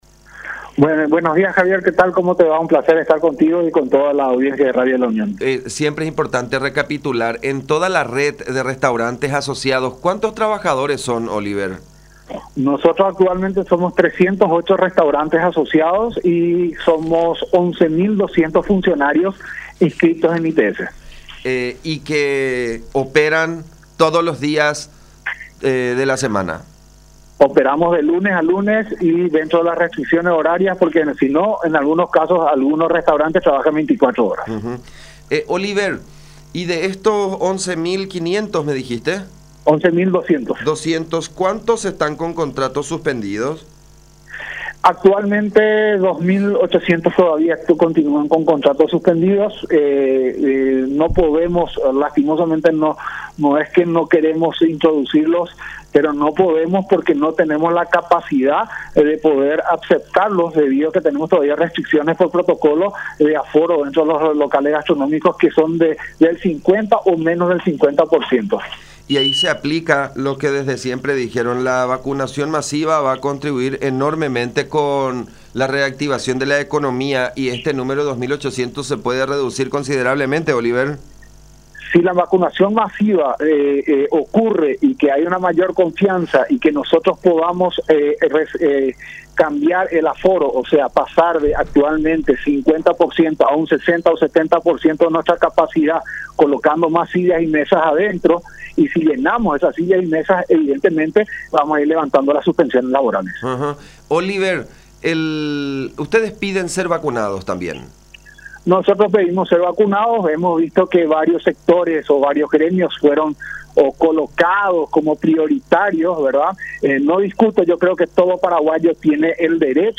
en conversación con Todas Las Voces por La Unión